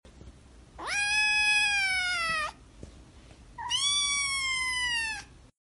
Cat Language (Part 3) 🥰 sound effects free download